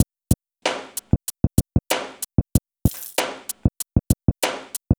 The assignment was to recreate a few drum breaks using non-drum base sounds of our choosing and then modifying them using DSP tricks.